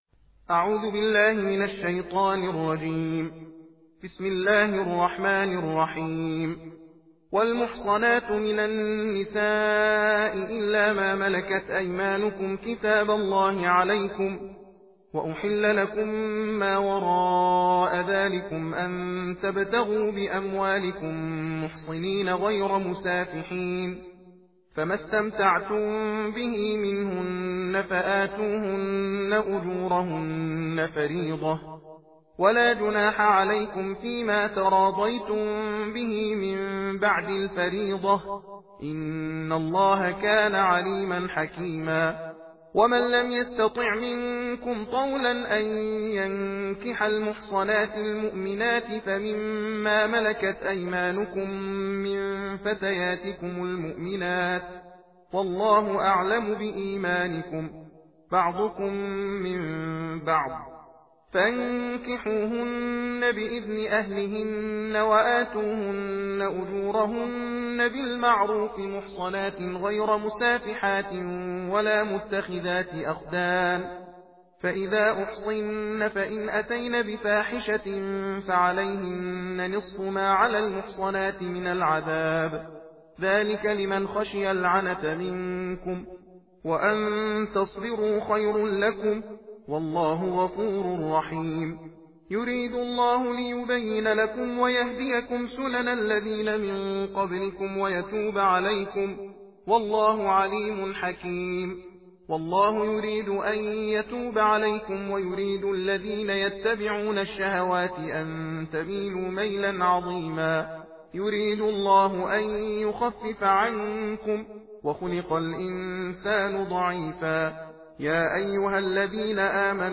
صوت/ تندخوانی جزء پنجم قرآن کریم